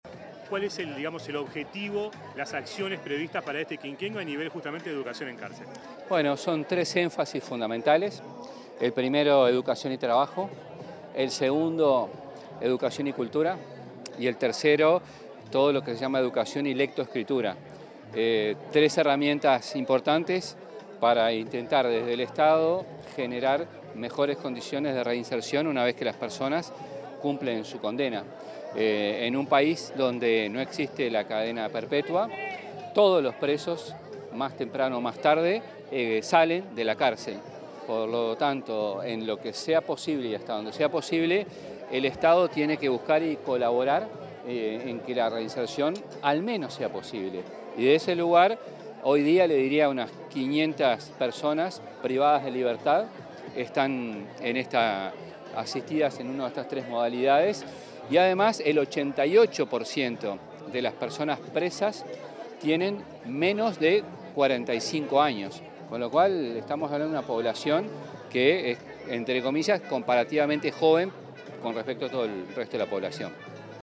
Declaraciones del ministro de Educación y Cultura, José Carlos Mahía
El ministro de Educación y Cultura, José Carlos Mahía, se expresó, en una rueda de prensa, acerca del Programa Nacional de Educación en Cárceles, cuyo